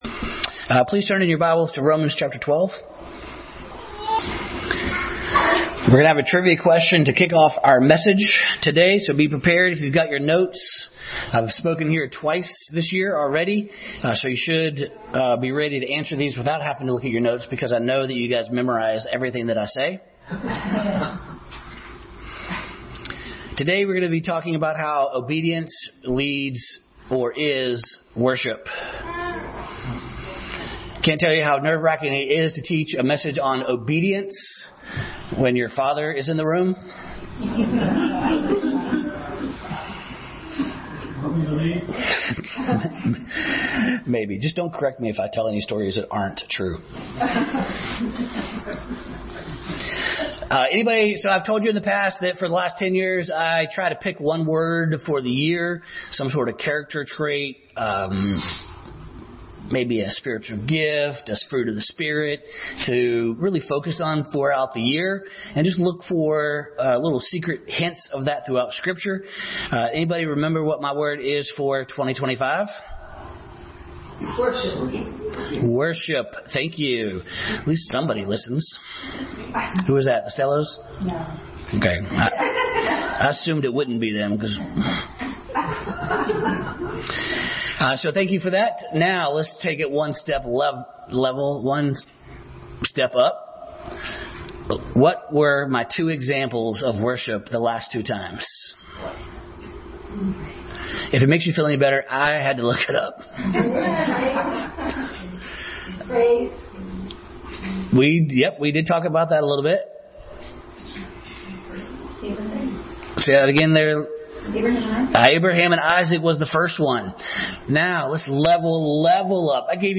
Obedience Passage: Romans 12:1-2, 8:28, 5:8, 10:13, Hebrews 10:8-14, Matthew 26:36, Philippians 2:5-8, Daniel 3:16-18 Service Type: Family Bible Hour